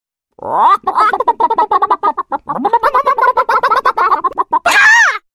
Hd Chicken Alarm-sound-HIingtone
hd-chicken-alarm.mp3